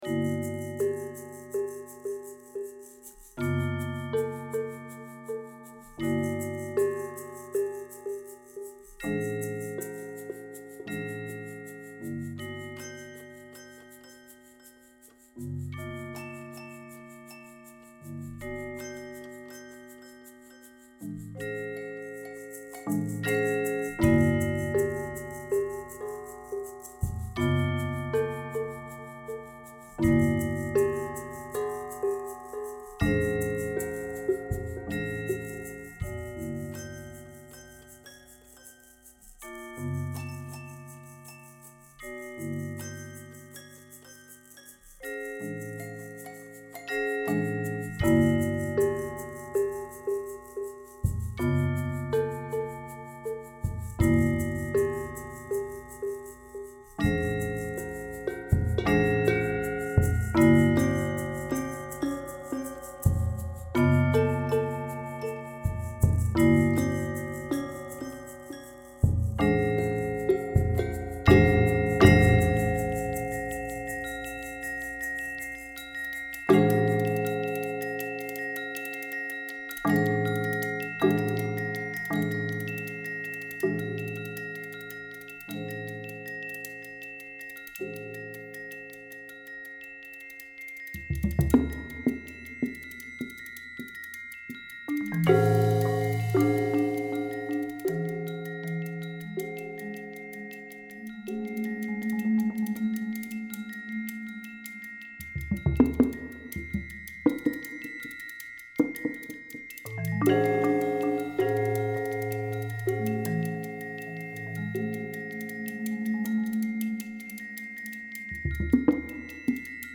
Genre: Percussion Ensemble
Glockenspiel
Vibraphone
Marimba [5-octave]